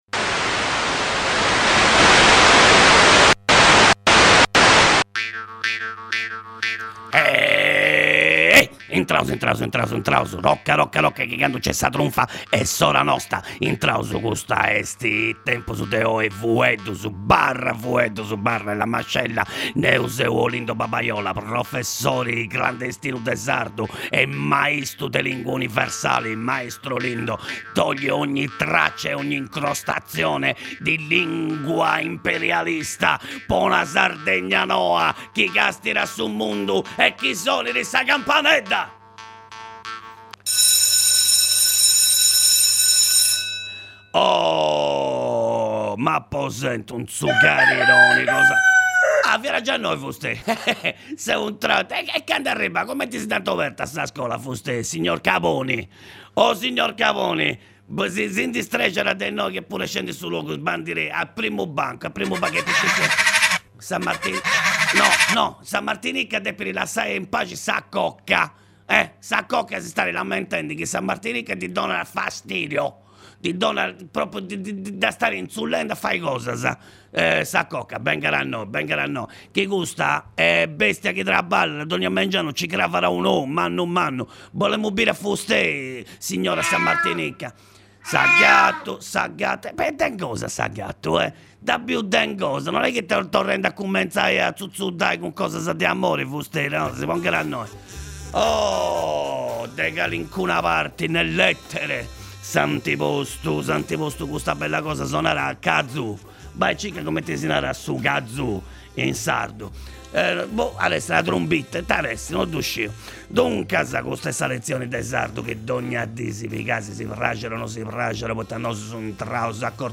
Professor Olindo Babaiola dogna di’ ìntrat a fura in is undas de Radio X po fai scola de sardu.